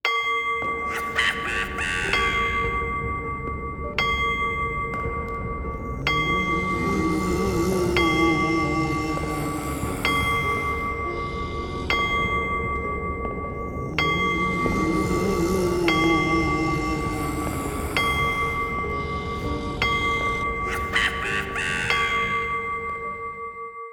cuckoo-clock-12.wav